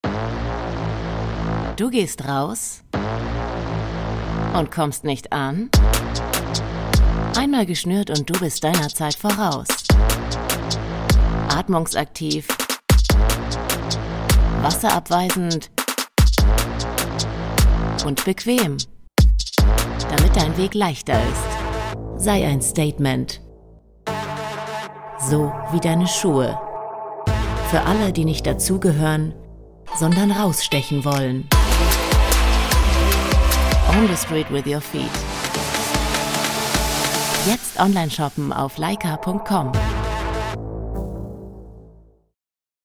dunkel, sonor, souverän, hell, fein, zart, markant, plakativ, sehr variabel
Mittel minus (25-45)
Schuh Werbung
Commercial (Werbung), Presentation